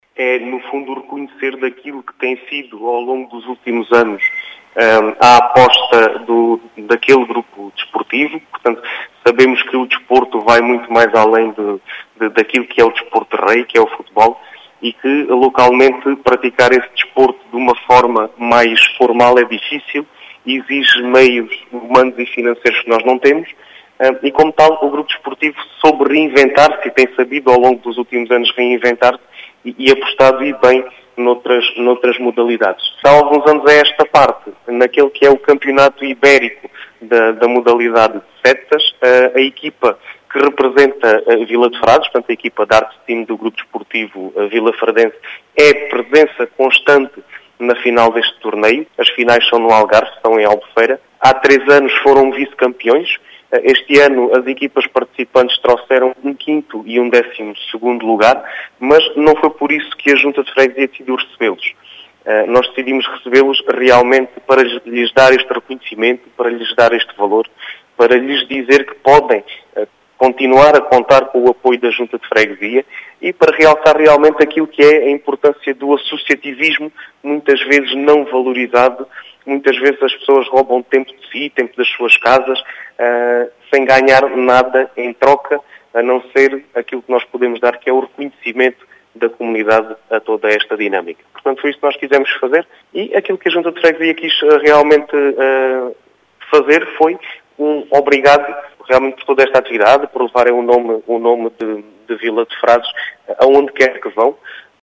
As explicações são de Diogo Conqueiro, presidente da junta de freguesia de Vila de Frades, que diz tratar-se de um “reconhecimento” pela sua actividade.